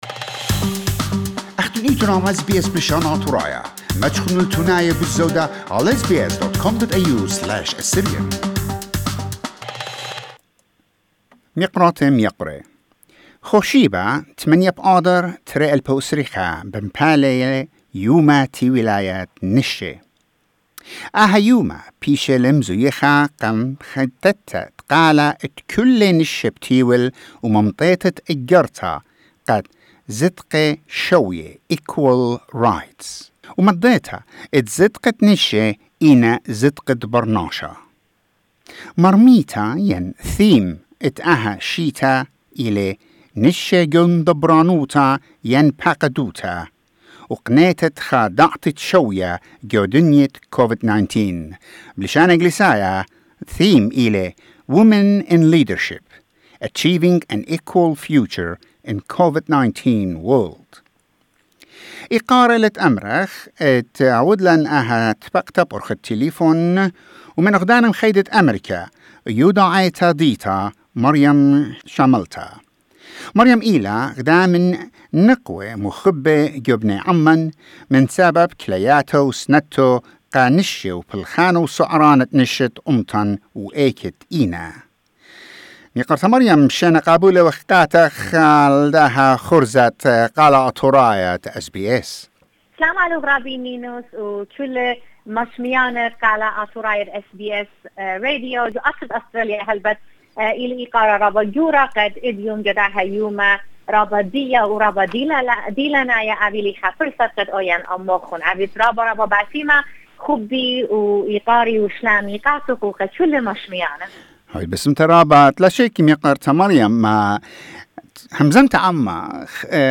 In an interview with SBS Assyrian